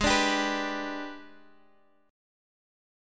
AbmM7b5 chord